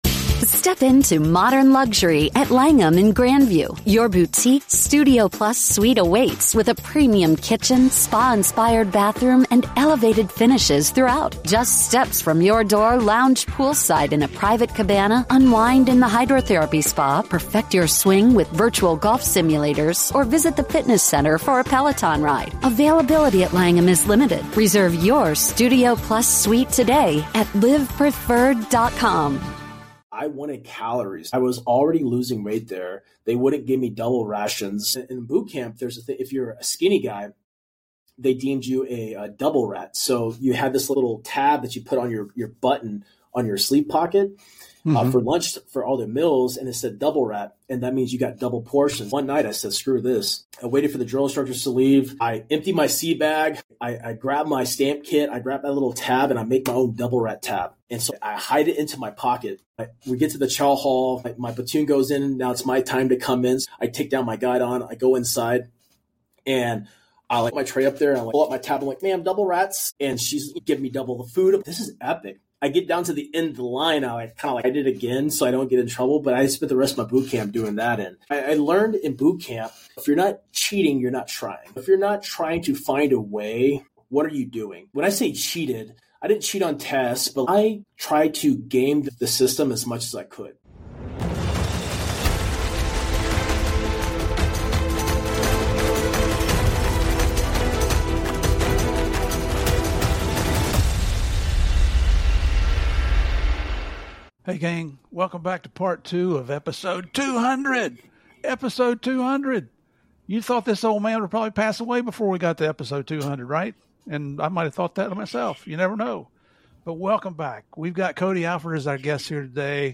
This raw and inspirational interview is a must-watch for fans of true crime, military heroes, and real-world leadership.